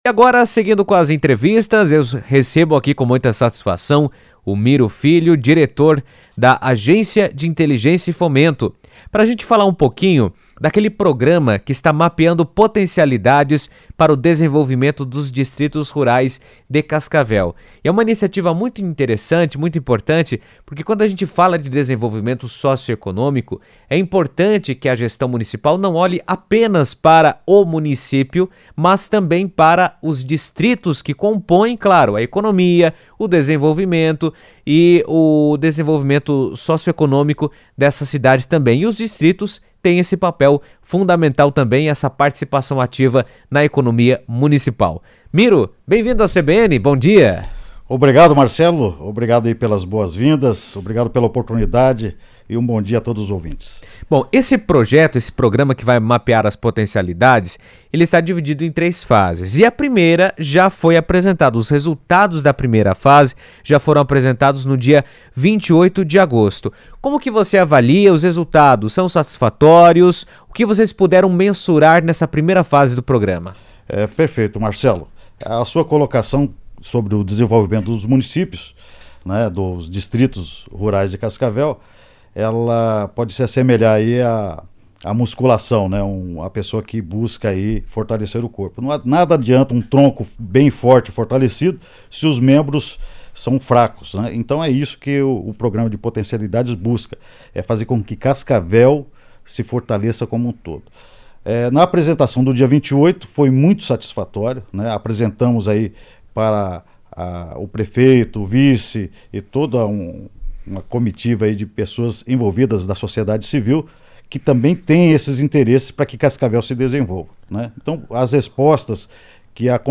Editoriais